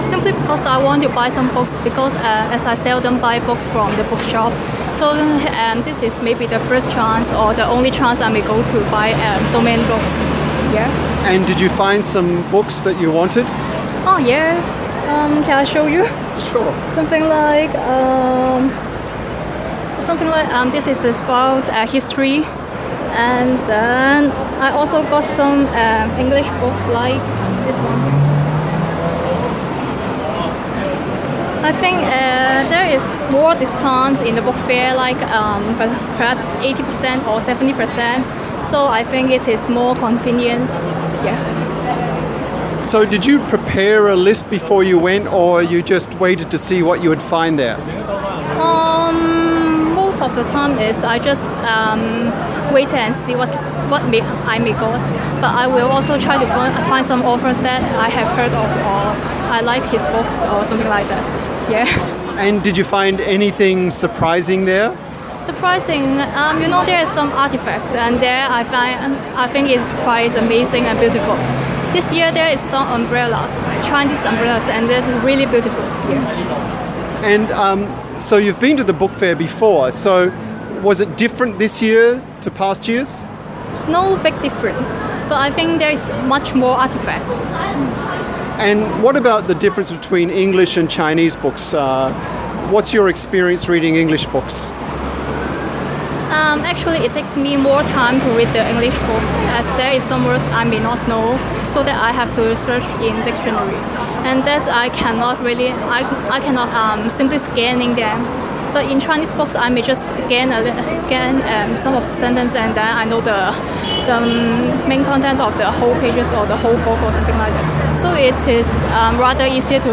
This Law student keeps an open mind when book shopping. She looks for books by familiar authors. She gets the gist of a story in Chinese by scanning the page but has to use the dictionary for English publications.